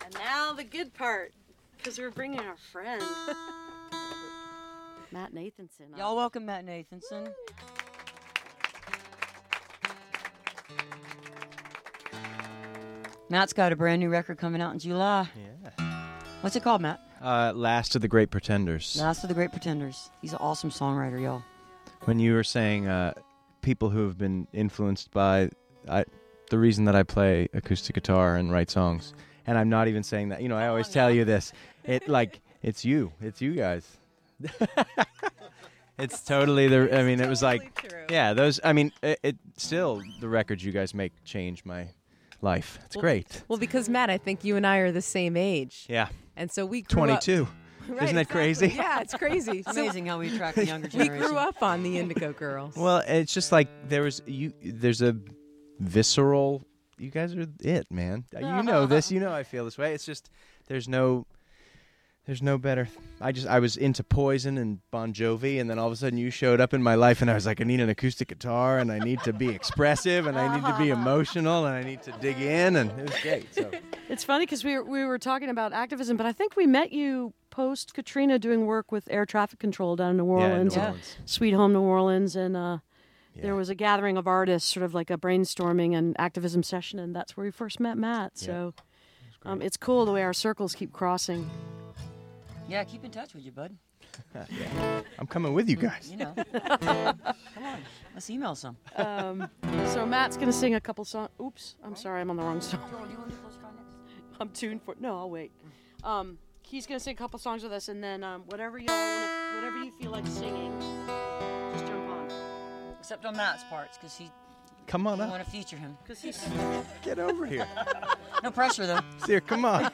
lifeblood: bootlegs: 2013-06-19: studio a - wfuv - new york, new york
07. interview (2:11)